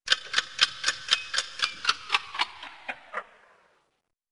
clockwithdoppler2.wav